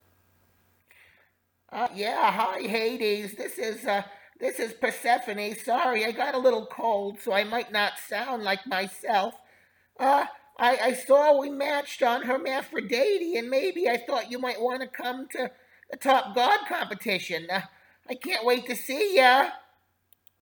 Click to listen to Hades Voicemail from... Persephone(?)